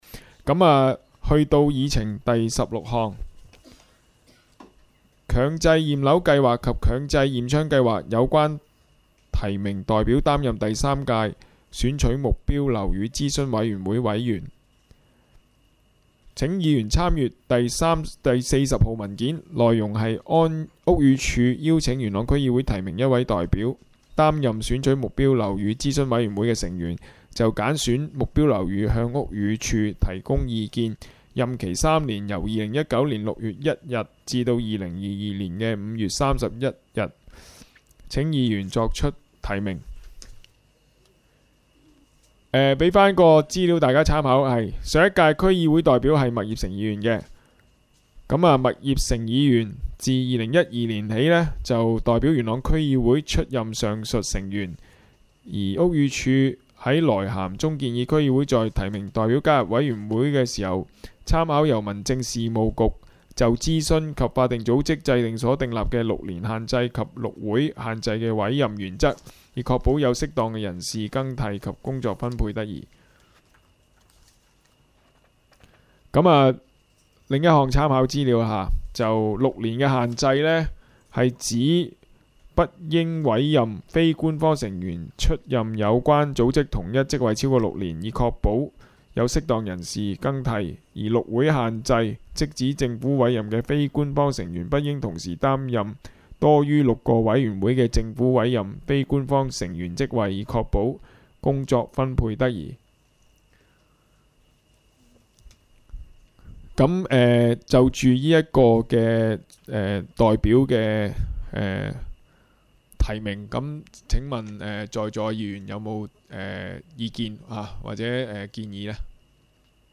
区议会大会的录音记录
元朗区议会第二次会议
地点: 元朗桥乐坊2号元朗政府合署十三楼会议厅